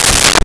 Door Heavy Close
Door Heavy Close.wav